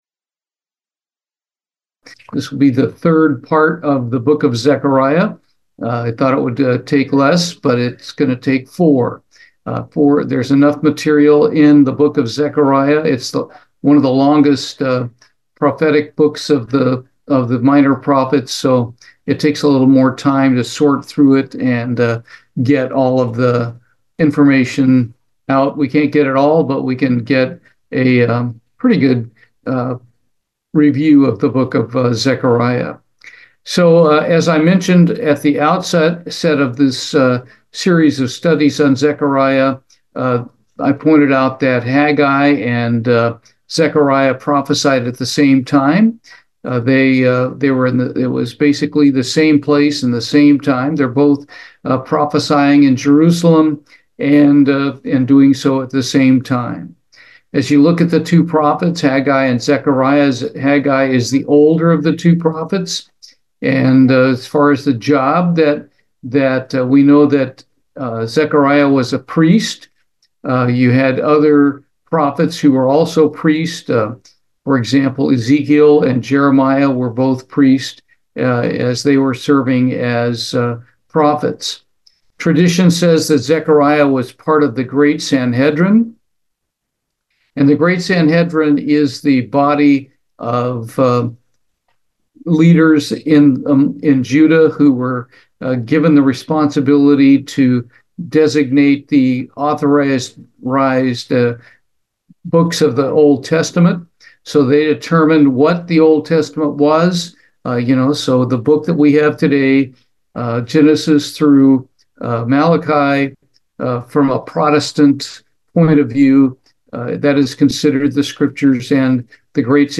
Bible Study, Zechariah, Part 3